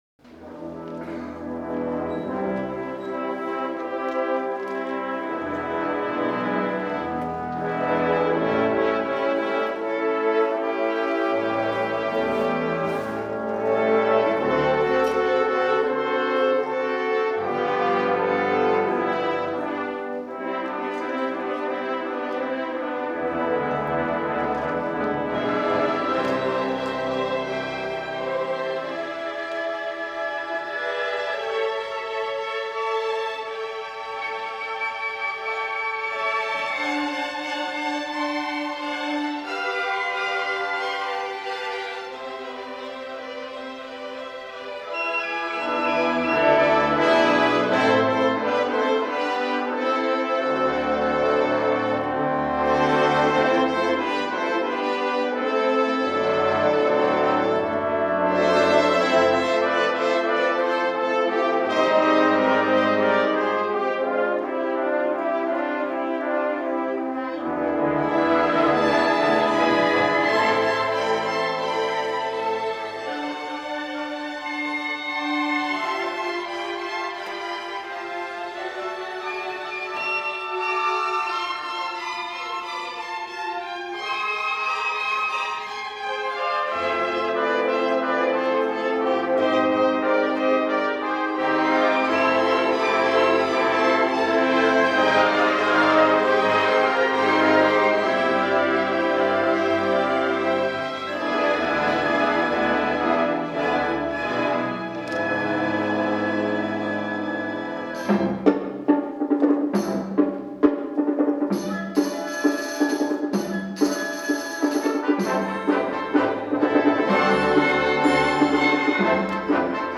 for Orchestra (2003)